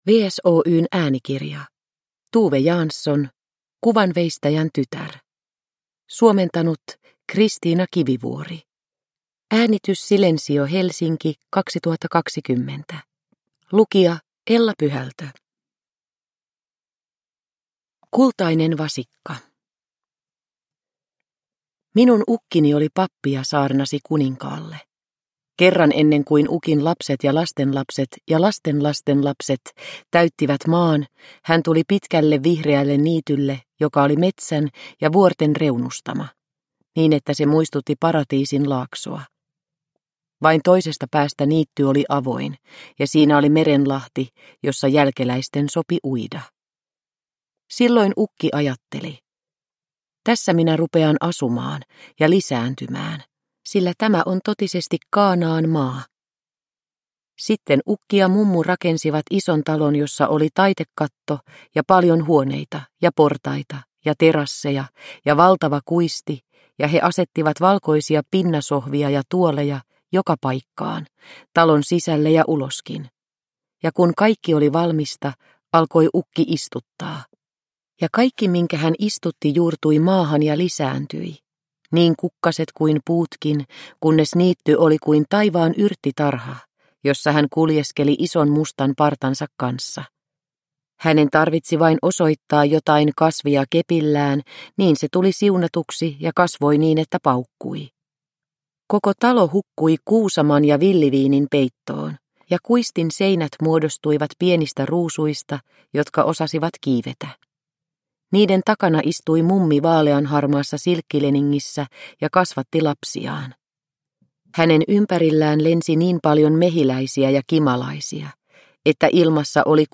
Kuvanveistäjän tytär – Ljudbok – Laddas ner